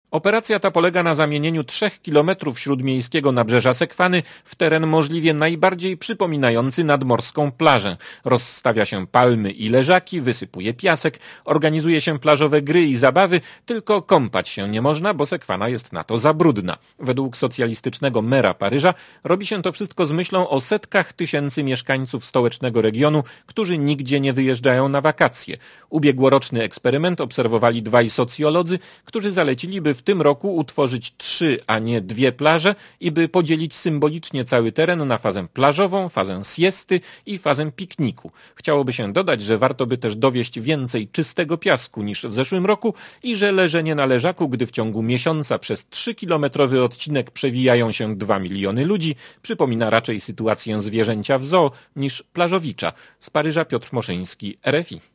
Korespondencja z Paryża (430Kb)